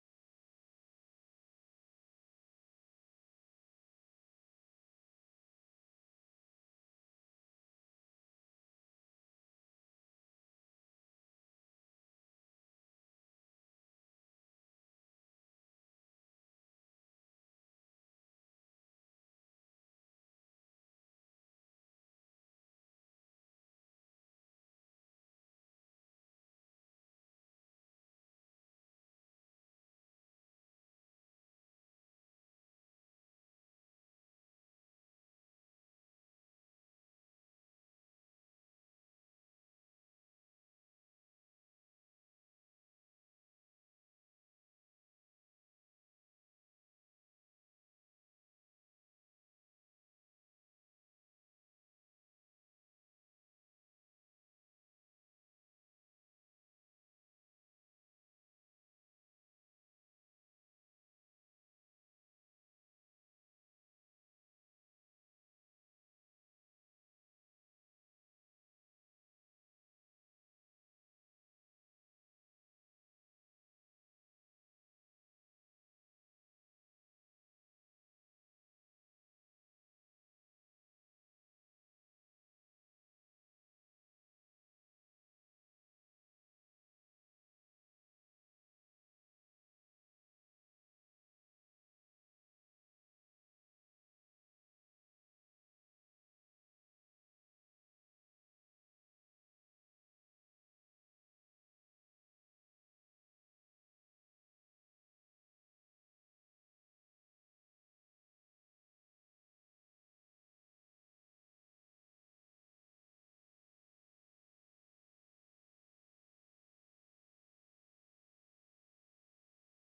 We Are In This Together is an unscripted live broadcast program that collects conversations and oral reflections of humans from different aspects of life in different parts of the world during the Corona Virus Pandemic and Social Isolation situation.